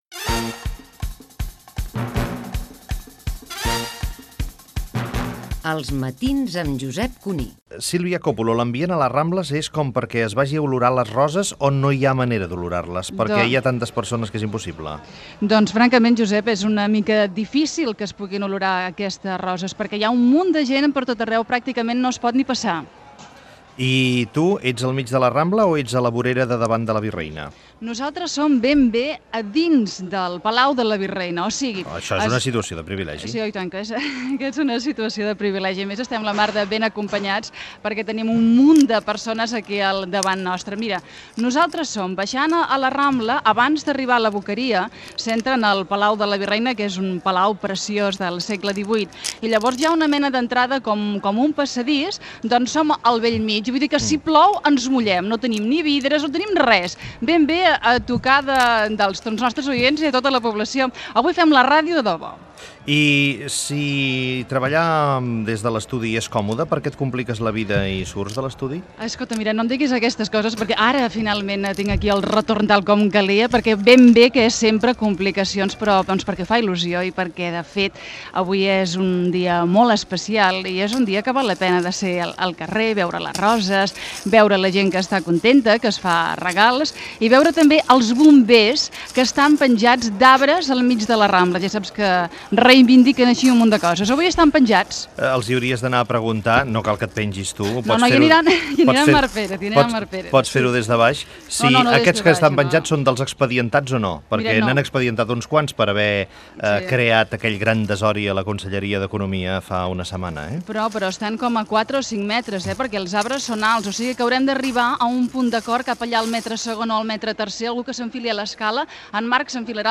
Indicatiu del programa, connexió amb el Palau de la Virreina, a la Rambla de Barcelona, amb motiu de la diada de Sant Jordi. Sílvia Cóppulo explica el que farà al seu programa. Informació del programa especial que es farà a la tarda, amb Elisenda Roca
Info-entreteniment